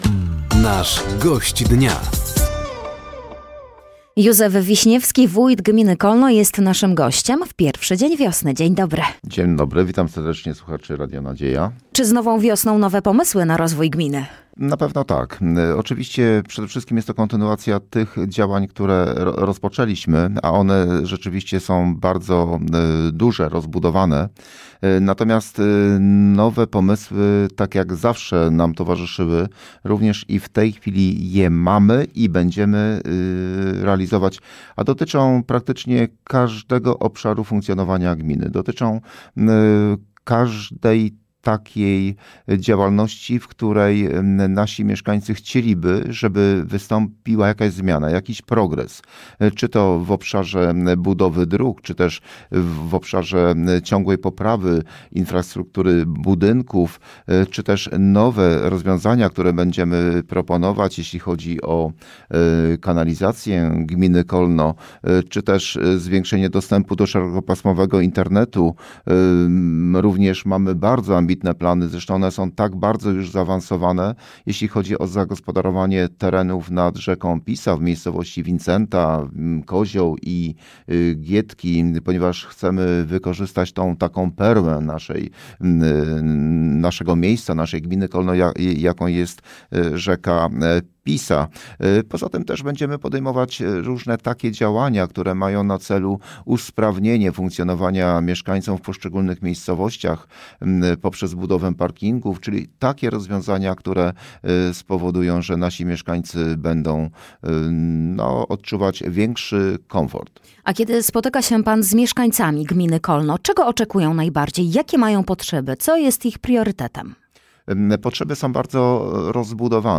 Gościem Dnia Radia Nadzieja był Józef Wiśniewski, Wójt Gminy Kolno. Tematem rozmowy były plany i pomysły na rozwój gminy. Józef Wiśniewski przedstawił także sytuację finansową gminy.